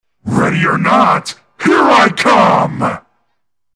ready or not here i come Meme Sound Effect
Category: Games Soundboard